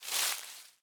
Minecraft Version Minecraft Version latest Latest Release | Latest Snapshot latest / assets / minecraft / sounds / block / leaf_litter / step6.ogg Compare With Compare With Latest Release | Latest Snapshot
step6.ogg